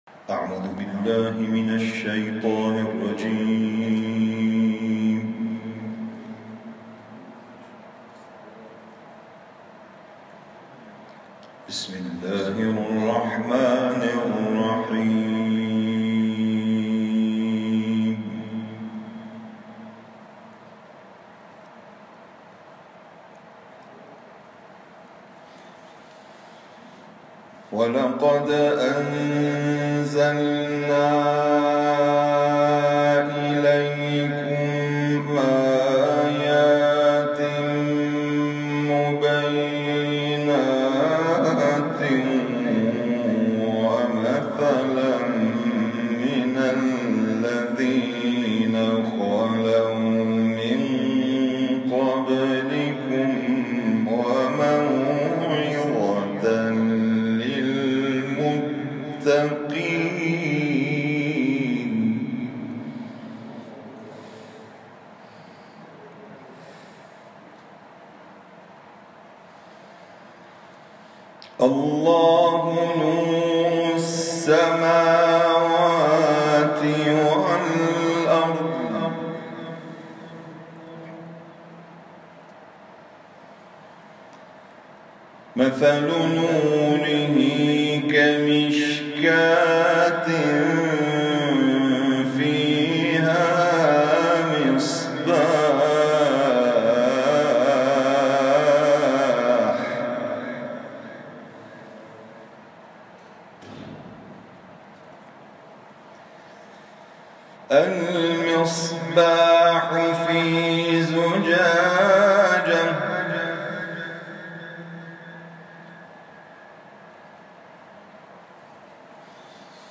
این تلاوت ۲۳ تیرماه، در کرسی تلاوت اذانگاهی حوزه علمیه امام خمینی(ره) ازگل اجرا شده است.